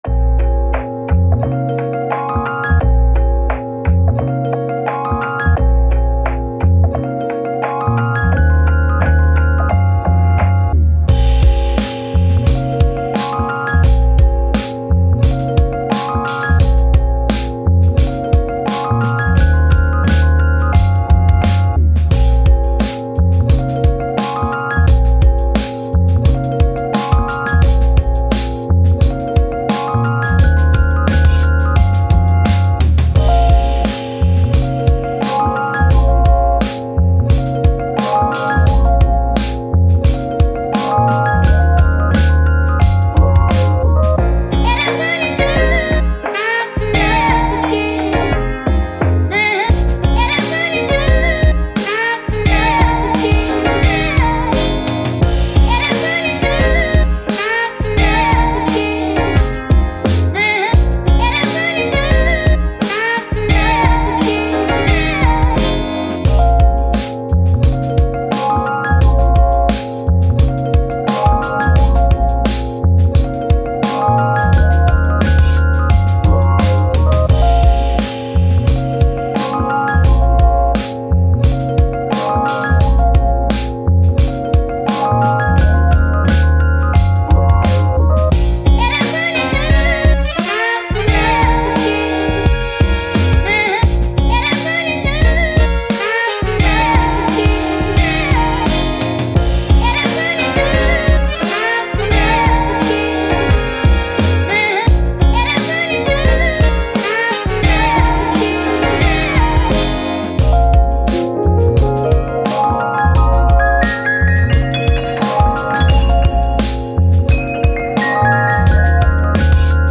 Hip Hop, Soulful, Jazzy, Upbeat Instrumental